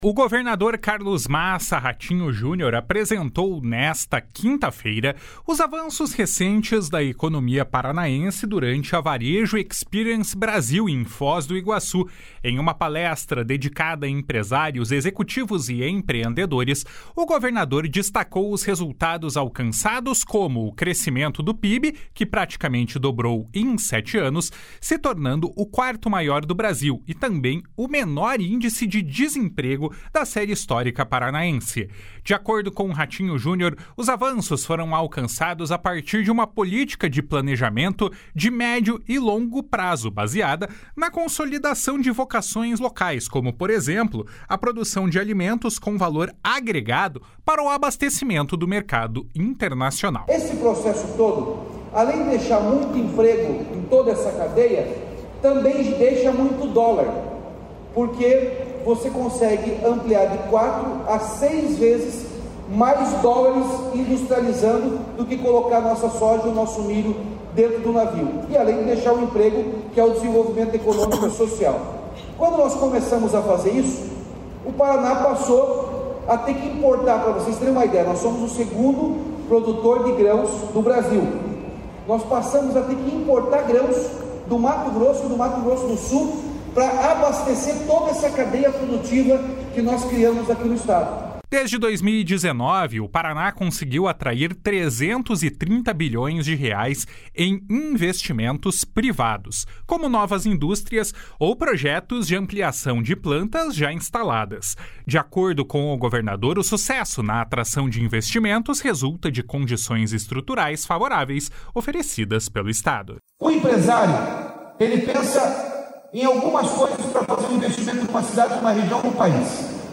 O governador Carlos Massa Ratinho Junior apresentou nesta quinta-feira os avanços recentes da economia paranaense durante a Varejo Experience Brasil, em Foz do Iguaçu.
// SONORA RATINHO JUNIOR //